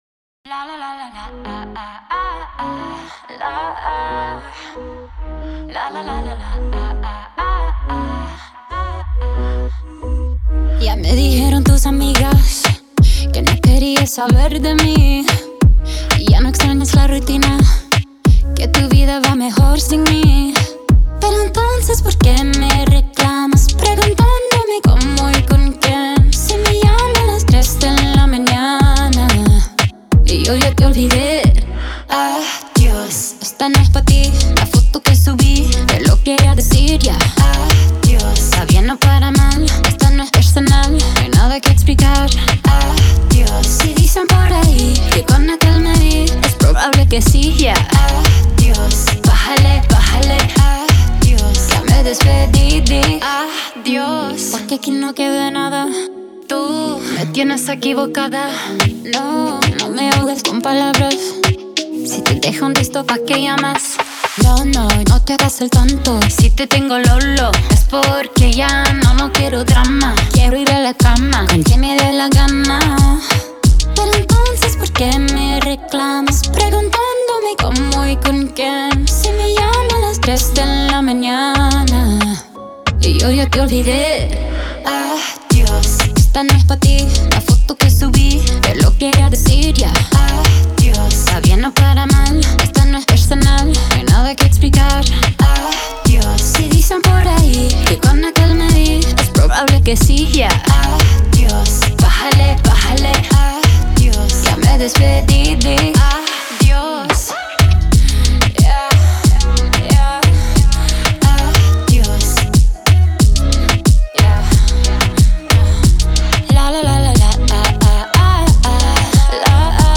мелодичными ритмами
выразительным вокалом